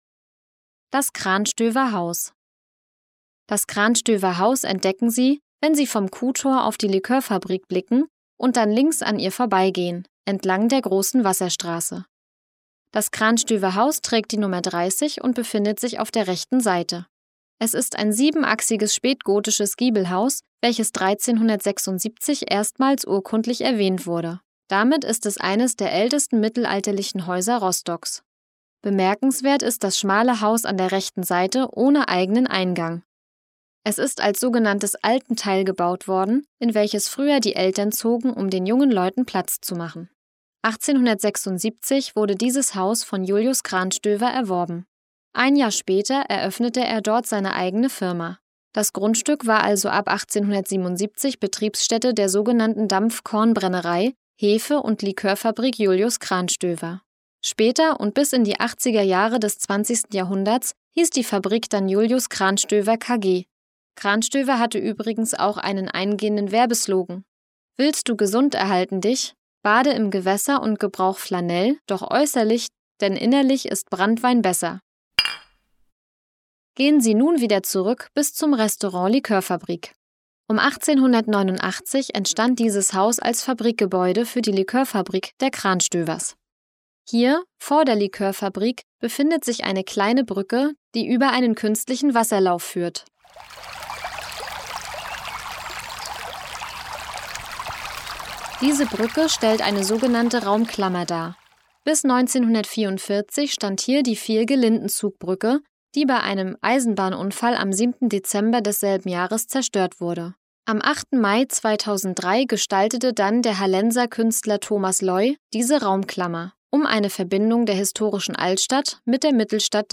Audioguide Rostock - Station 14: Krahnstöver Haus